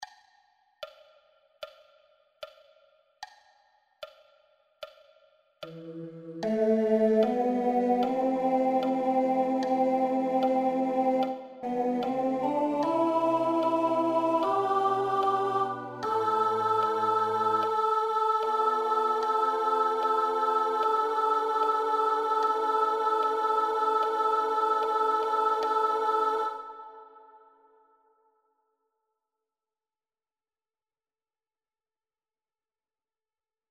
Key written in: A Minor